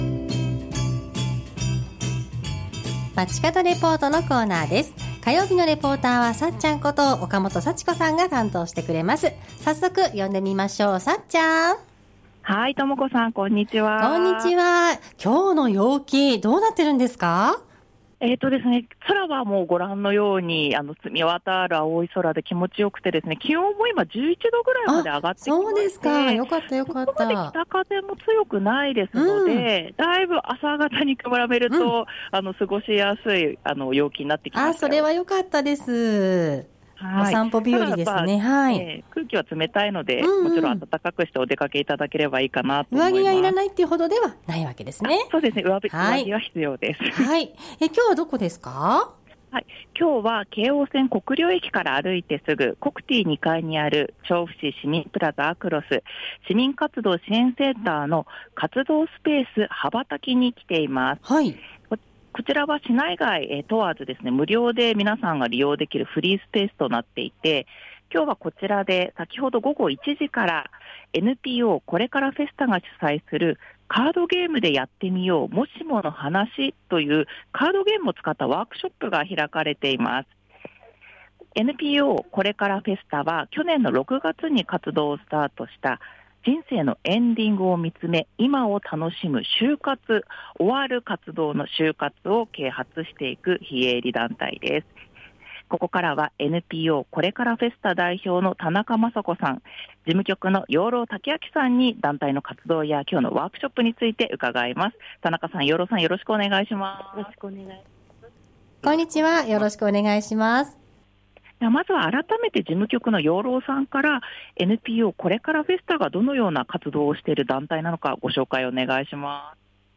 午後のカフェテラス 街角レポート
中継は京王線・国領駅から歩いてすぐ、コクティー2階にある調布市市民プラザあくろす・ 市民活動支援センターの「活動スペースはばたき」からお届けしました。